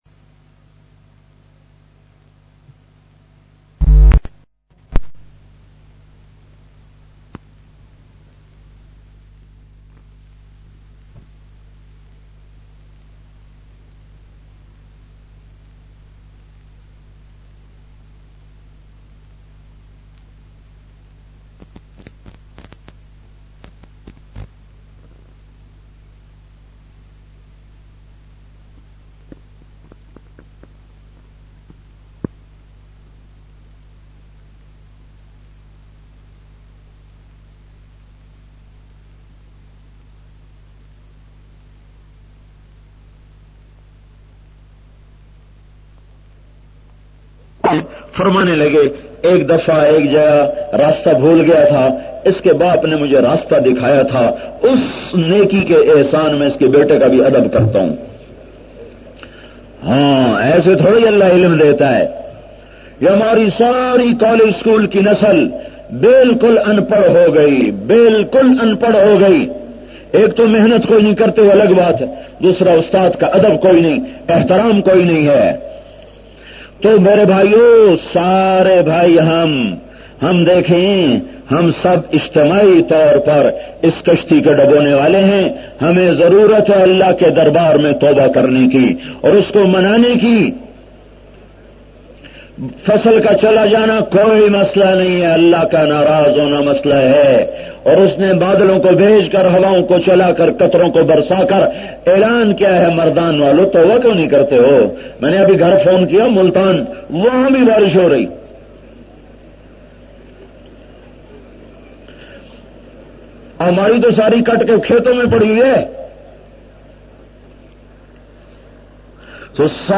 ALLAH naraz ha bayan mp3